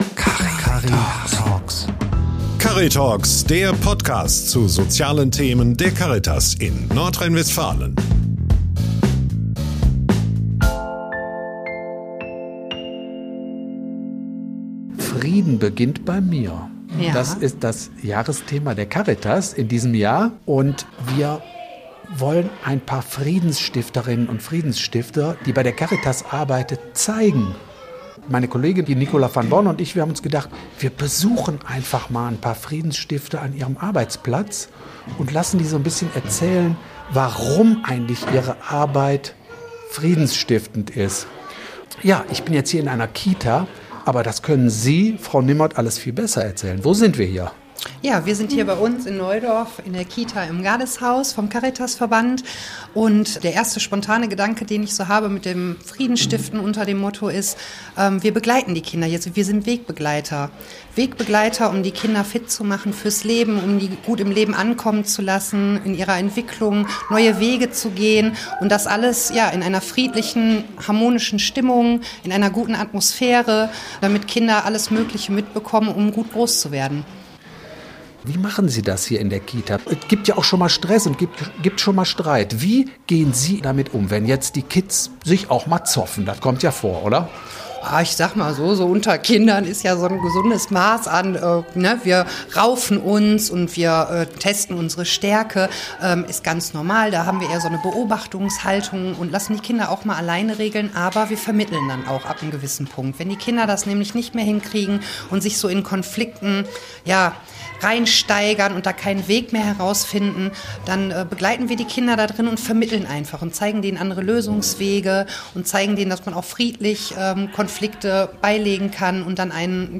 Im Gespräch erklärt sie, warum Unfrieden auch bei den Kindern schon ein Thema ist, und wie sie und ihr Team mit den Ängsten der Kids umgehen.